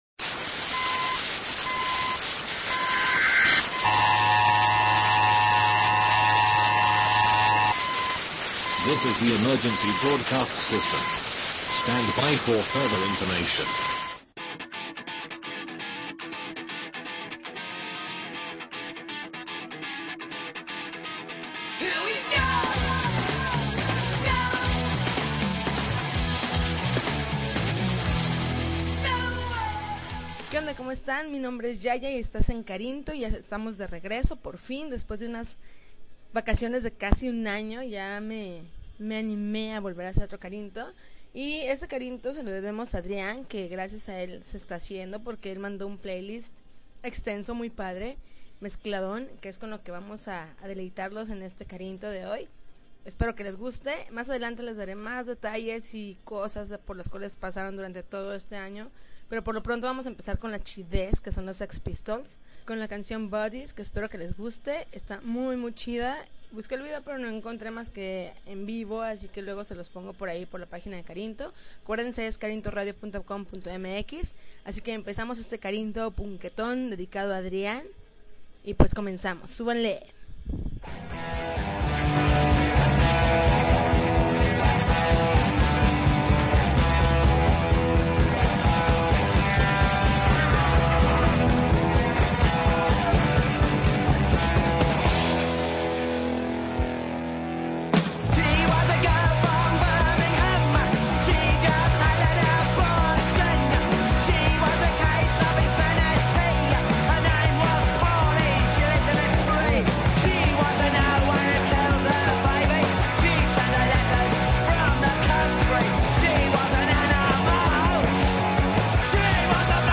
June 5, 2012Podcast, Punk Rock Alternativo